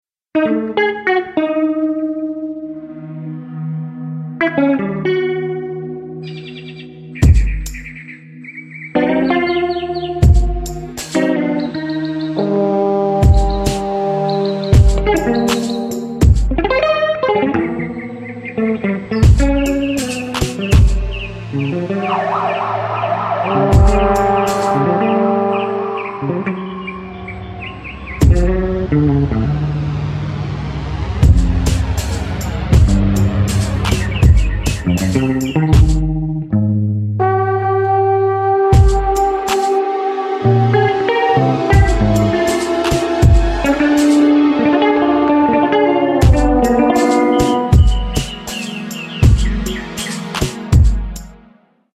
MIDI trombone
guitars